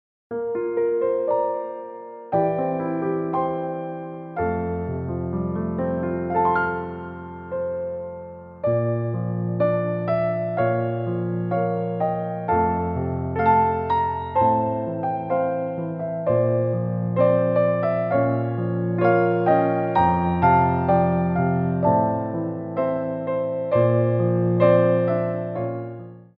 Piano Arrangements
Pliés 1
4/4 (16x8)